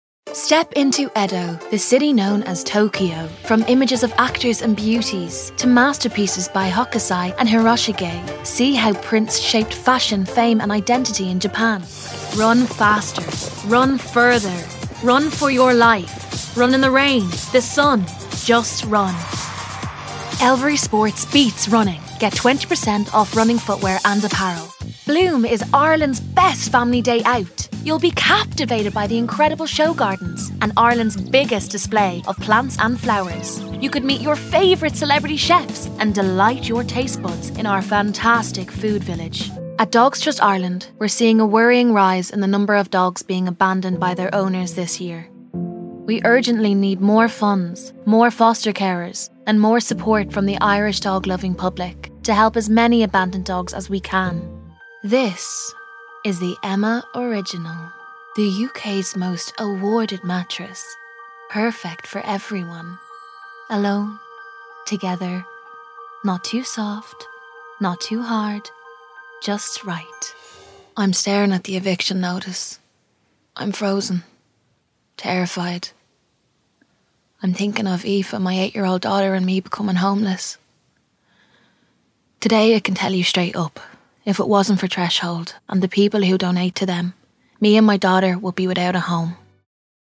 Female
20s/30s
Irish Dublin Neutral